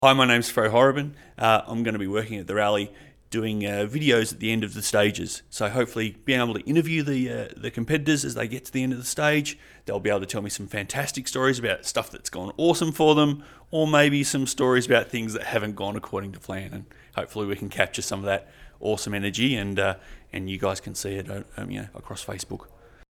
RADIO GRABS